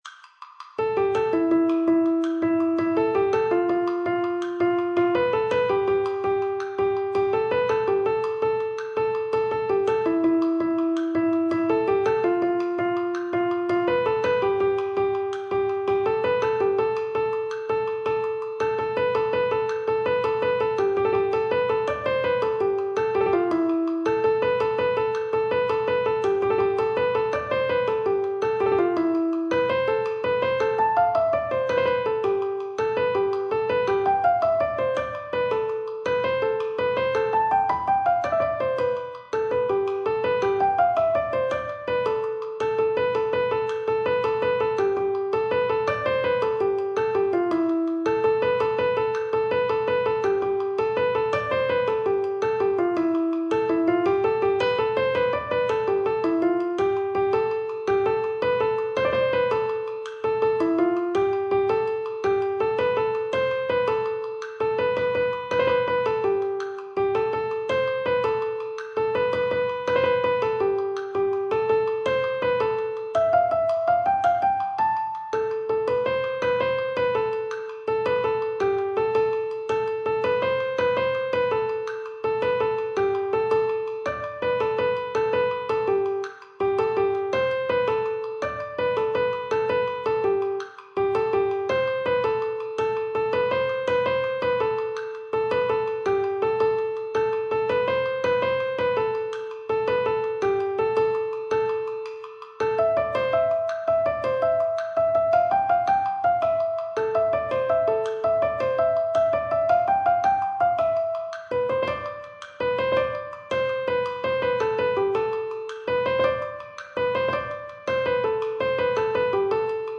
سطح : ساده